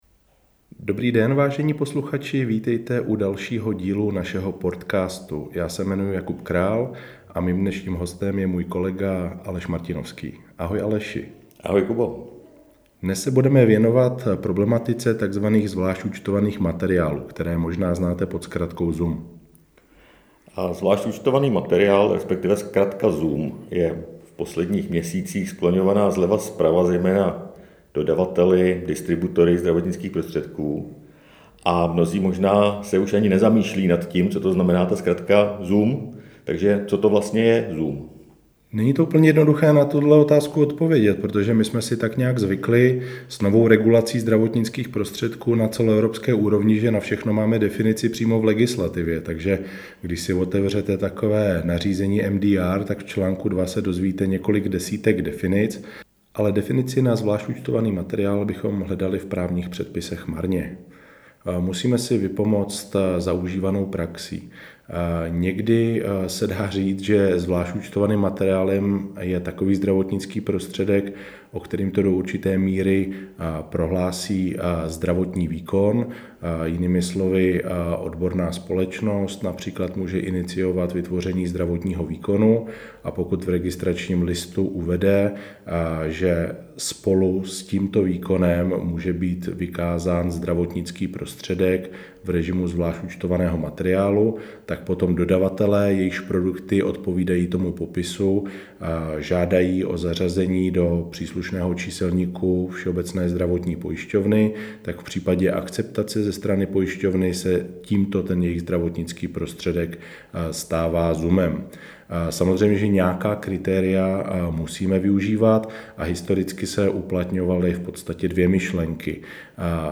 Cílem cca třinácti minutového rozhovoru je posluchačům stručně vysvětlit základy tohoto institutu, na které navážeme v rámci podzimního webináře Základy cenové a úhradové regulace zdravotnických prostředků .